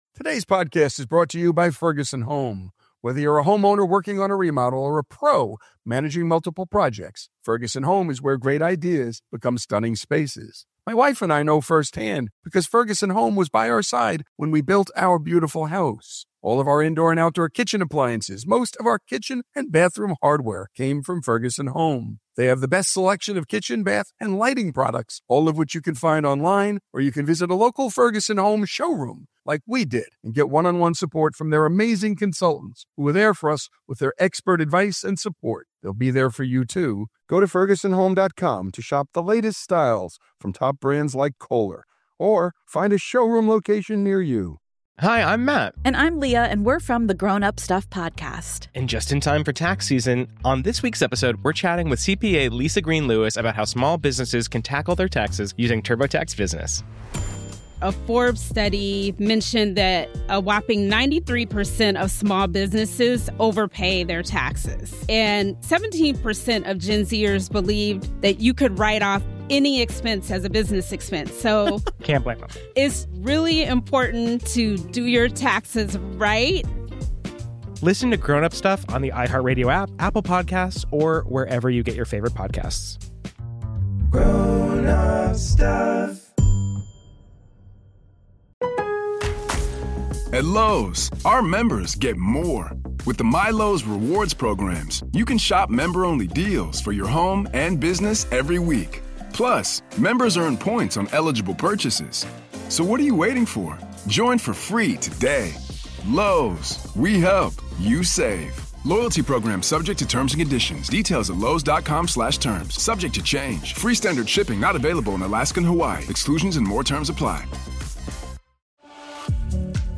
On this episode of Our American Stories, in honor of the late justice's birth, Author James Rosen tells the story of Antonin Scalia's unlikely but inevitable rise to the U.S. Supreme Court.